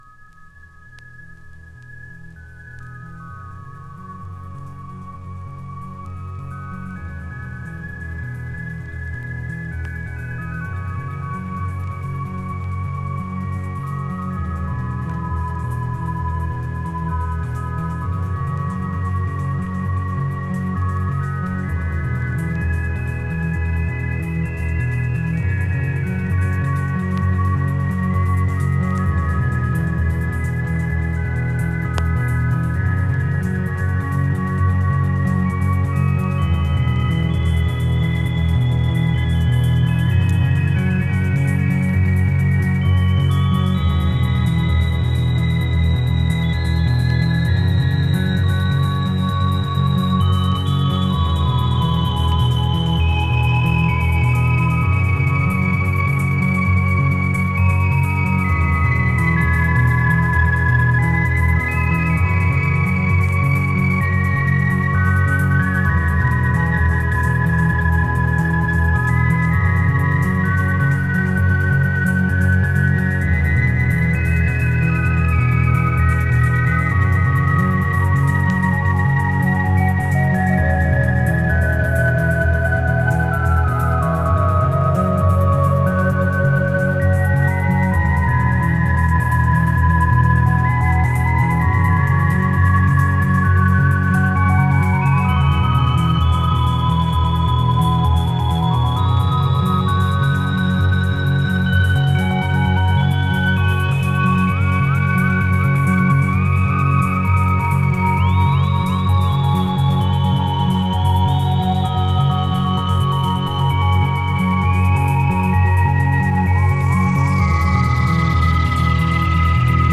シンセサイザーとシークウェンサーを導入したスペイシー＆コズミックなサウンド。